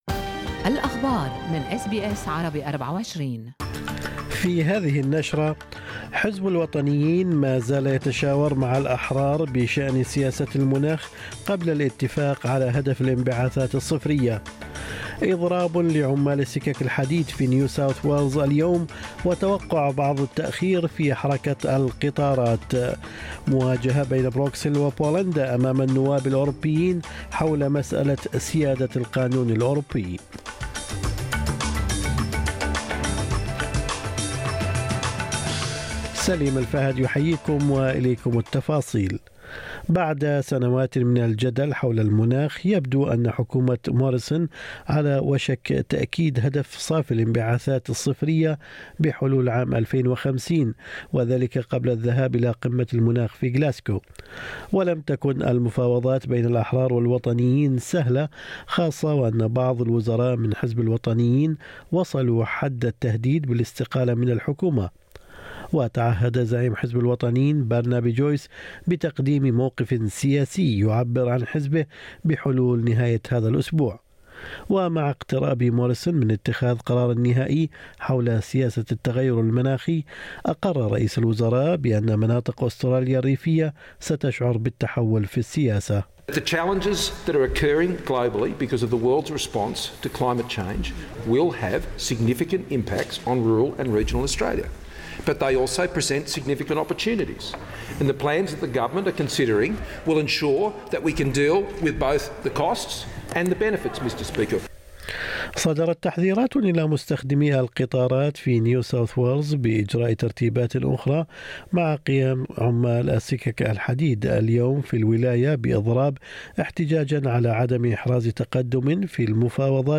نشرة أخبار الصباح 20/10/2021